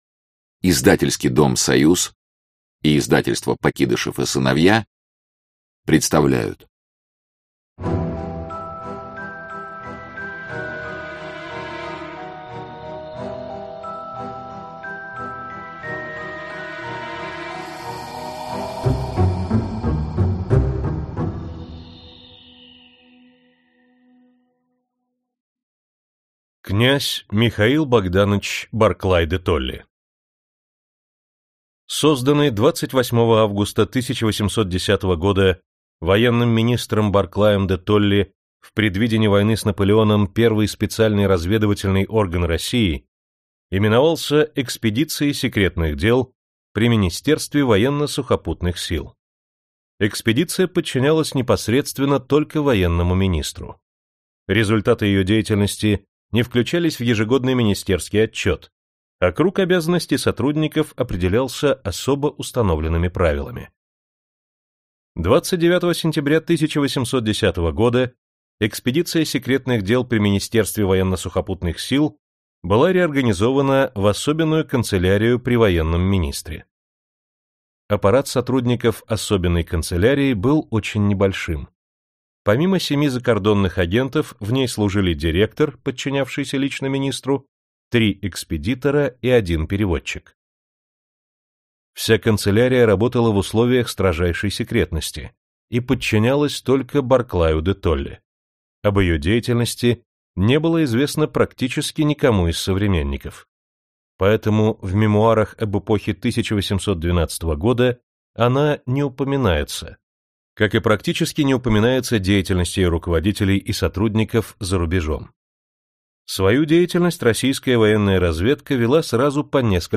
Аудиокнига Разведка Российской Империи | Библиотека аудиокниг